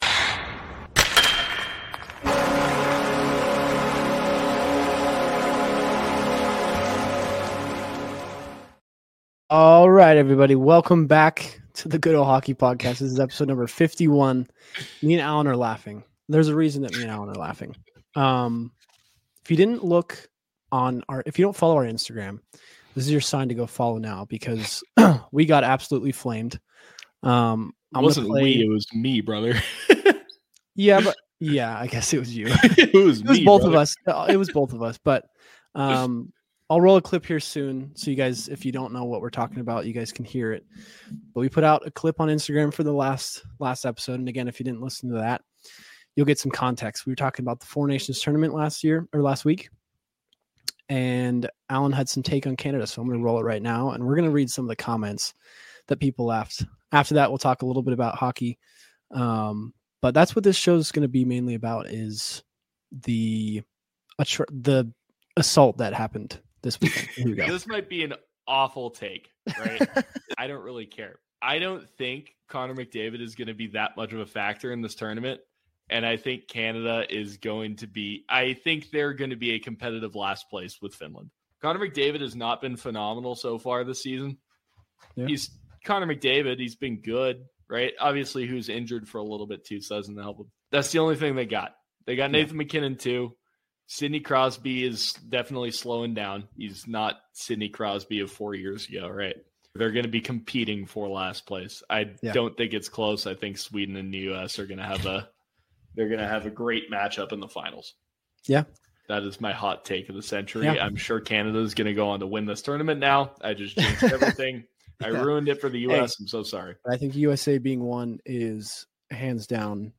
They discuss Conor McDavid's recent performance and the resulting social media backlash. The episode also covers the impact of allowing CHL players to play in NCAA hockey and their thoughts on future developments in college hockey. The hosts read and react to comments from fans and critics alike, and they wrap up by highlighting some of the exciting prospects in NCAA hockey.